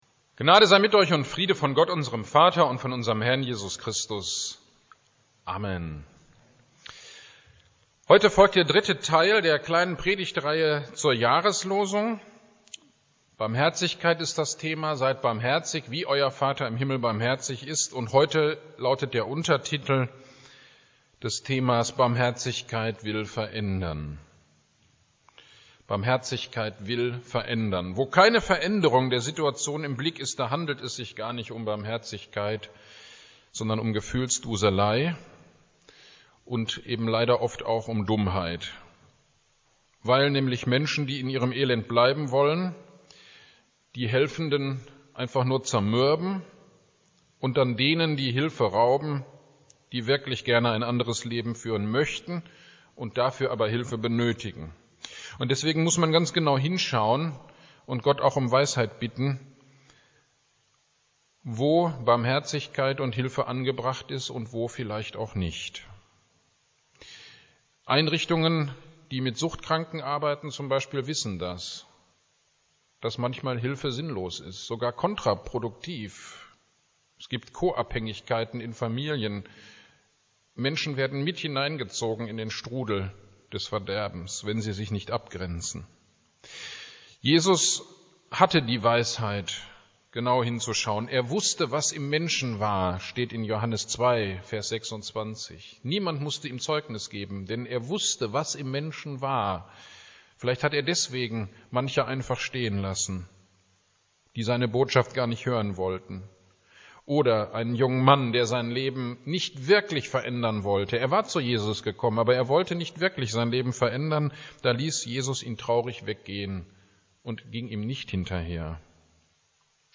Predigt am 31.01.21 - Barmherzigkeit will verändern - Kirchgemeinde Pölzig